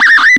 Le fonctionnement est bizarre, car les sons qui sortent sont différents après chaque utilisation - mais pas tout le temps.
Vous aussi vous voulez utiliser le Synthétiseur Aléatoire du Microcosme ?